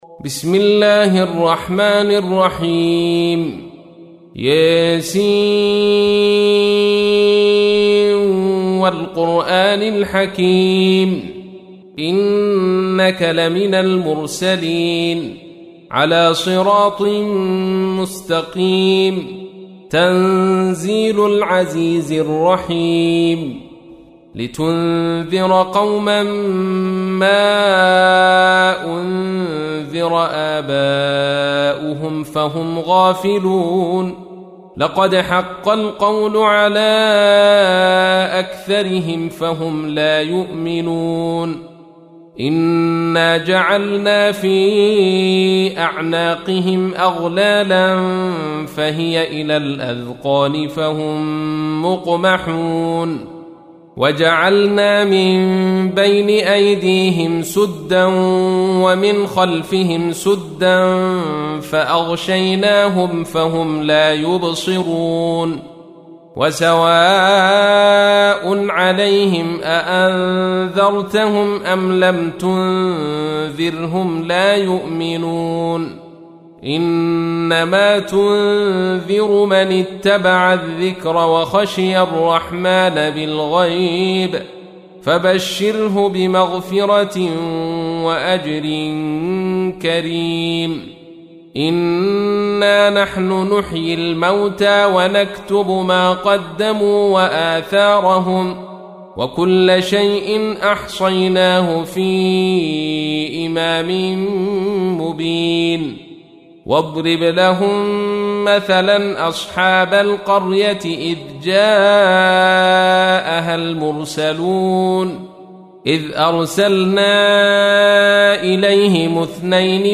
تحميل : 36. سورة يس / القارئ عبد الرشيد صوفي / القرآن الكريم / موقع يا حسين